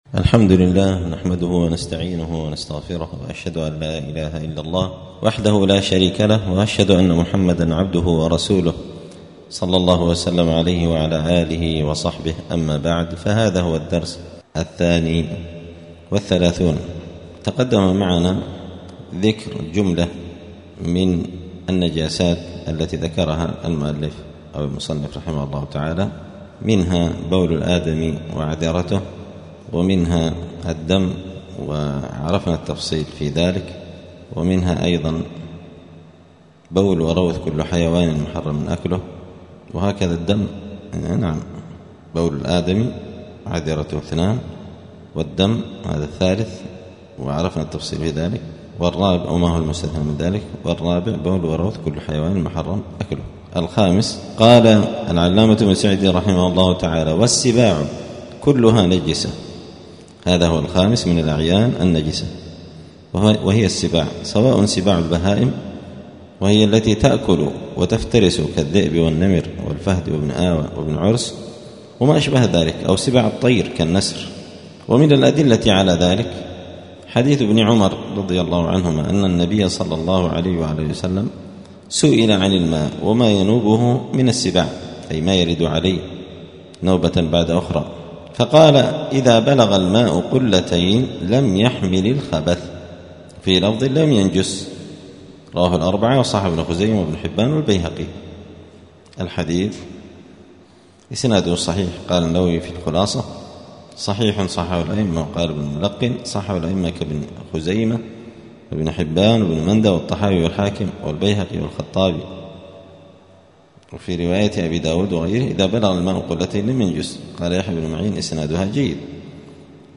*الدرس الثاني والثلاثون (32) {كتاب الطهارة باب الاستنجاء وآداب قضاء الحاجة حكم نجاسة السباع}*